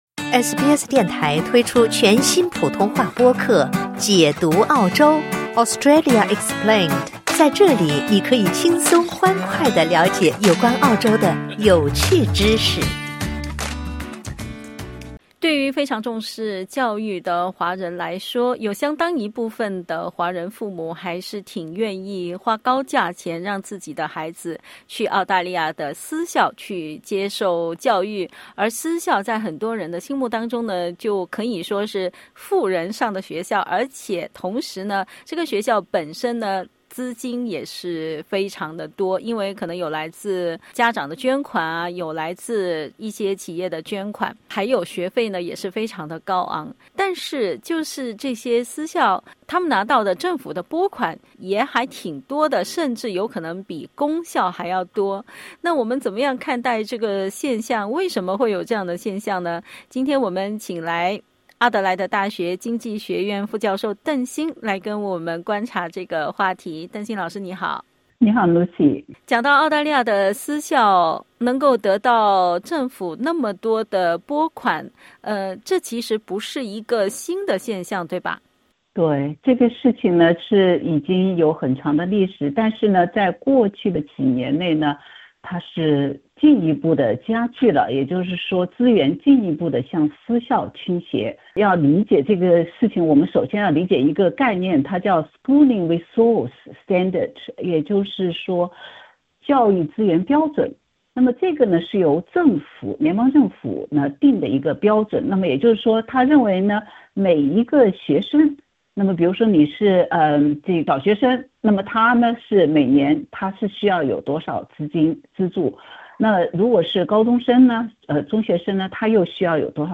“有钱的”私校被政府资金过度资助，而一些缺钱的公立学校则得不到急需的资金，这和联盟党政府时期的一个法例有关。（点击音频收听详细采访）